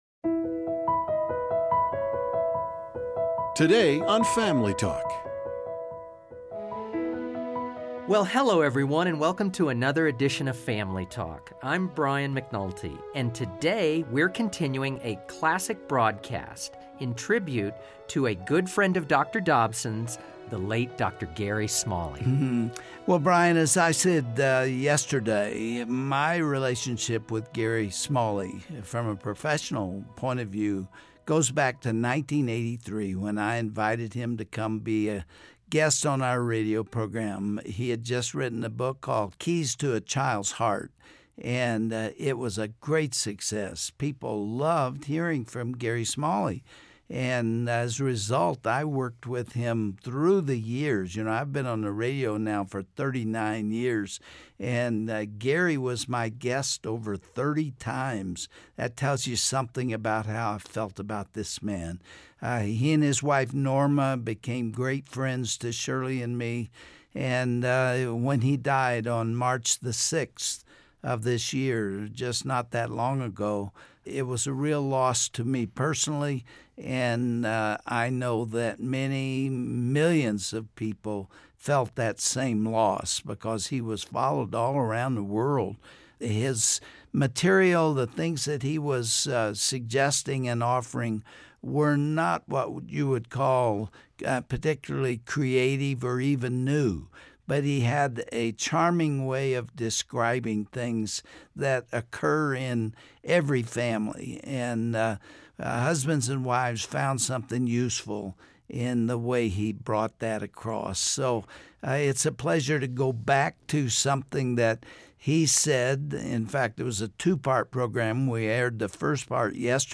On this classic edition of Family Talk, Dr. James Dobson pays tribute to the late Gary Smalley by airing one of his most popular broadcasts. Dr. Smalley explains how creating a family constitution can strengthen your family...